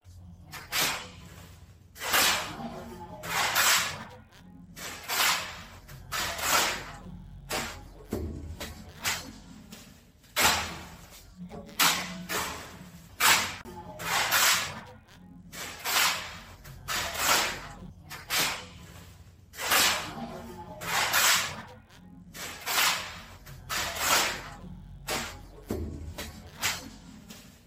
Tiếng trộn Hồ, trộn Vữa, trộn Bê tông, Xi măng… bằng Tay
Tiếng Phụ Hồ, trộn Vữa, trộn Cát, Sạn Bê tông… bằng Tay Tiếng Xúc cát sạn vào máy Trộn Hồ, Trộn Bê Tông, Xi măng…
Thể loại: Tiếng đồ vật
Description: Âm thanh sột soạt, lạo xạo, trộn hồ thủ công, trộn vữa bằng tay, trộn bê tông xẻng tay, trộn xây dựng không máy, âm thanh lao động xây dựng, tiếng lạo xạo xẻng, tiếng trộn vật liệu xây dựng, đặc trưng phát ra khi người thợ... những nguyên liệu xây dựng đặc sệt, nặng tay. Tiếng xẻng quệt vào đáy thùng hoặc nền đất vang lên những nhịp kéo xoẹt, rít nhẹ, đan xen tiếng sột soạt của cát, xi măng.... Đây là âm thanh quen thuộc tại các công trình xây dựng thủ công, mô phỏng cảnh lao động xây dựng chân thực rất Việt Nam.
tieng-tron-ho-tron-vua-tron-be-tong-bang-tay-www_tiengdong_com.mp3